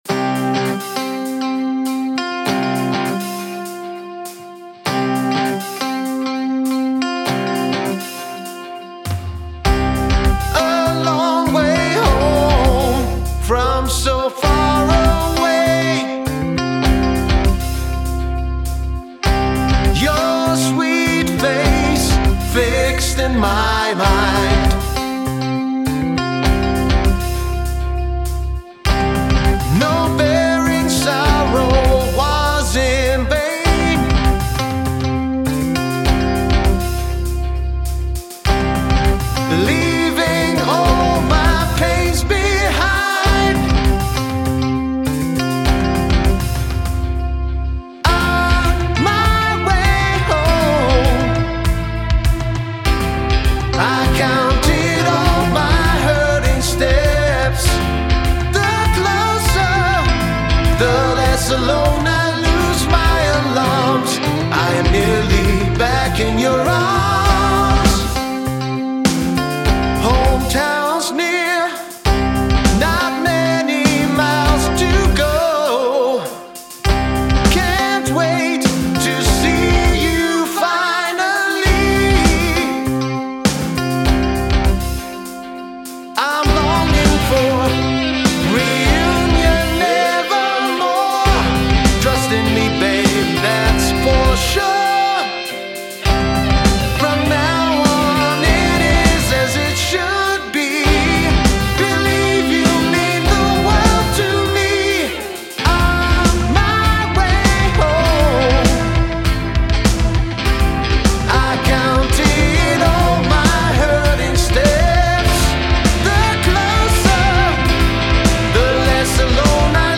Hier bei Dir klingts sehr gut,und vor allem auch sauber. Guter Raum, gute Arbeit in kurzer Zeit!
OK, ich hab Dir jetzt den Fade am Ende noch gesetzt und auch vorne bei den irritierenden t-t-t-t Delays eine Automation gemacht.